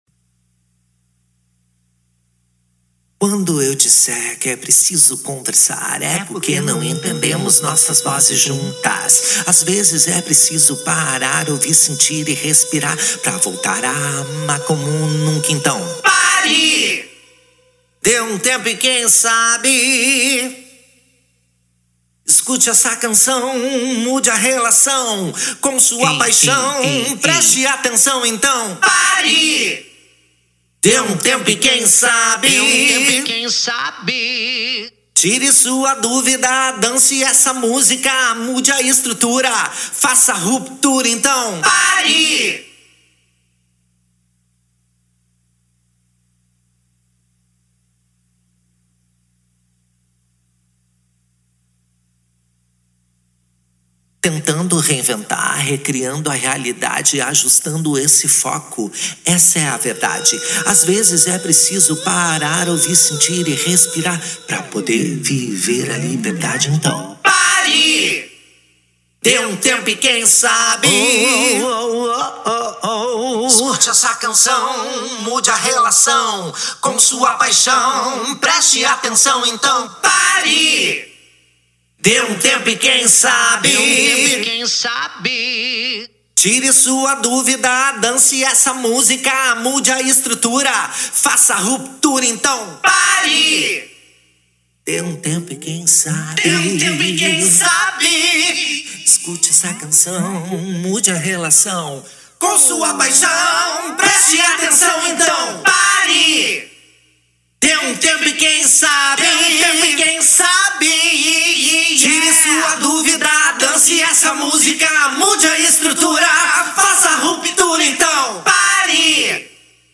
EstiloPop
Cidade/EstadoPorto Alegre / RS